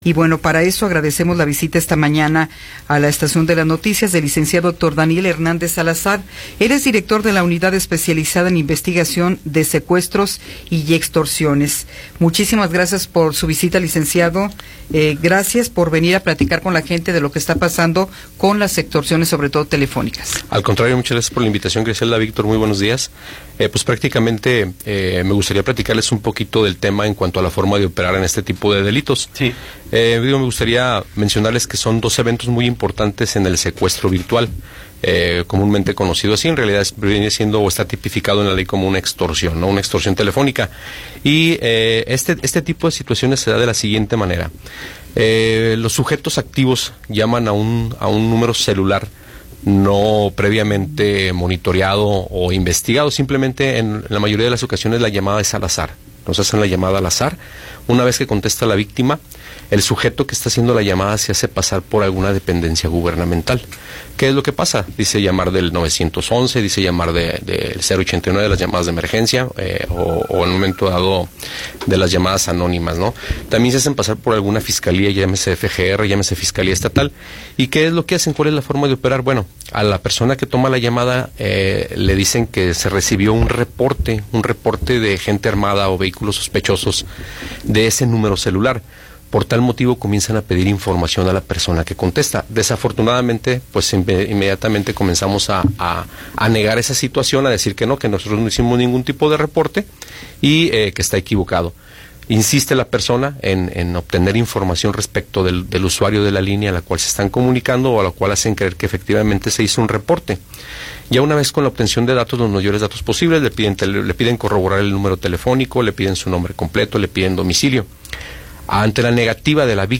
Entrevista con Héctor Daniel Hernández Salazar
Héctor Daniel Hernández Salazar, director de la Unidad de Investigación Especializada en Secuestros y Extorsiones, nos habla sobre el secuestro virtual y extorsiones telefónicas.